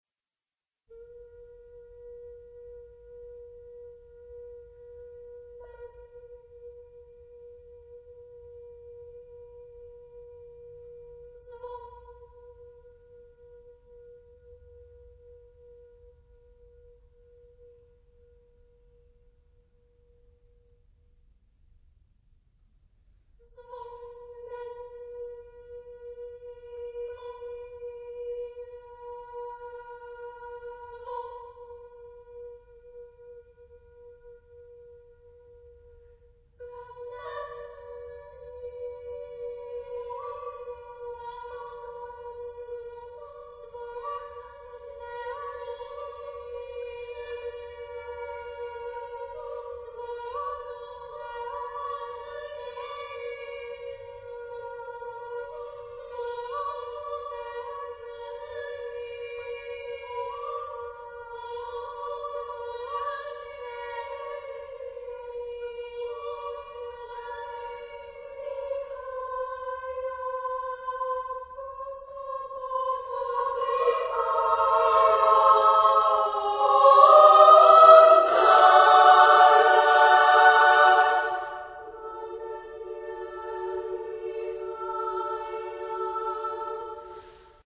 Genre-Style-Form: Suite ; Contemporary ; Secular
Mood of the piece: mystical
Soloist(s): Soloist group  (6 soloist(s))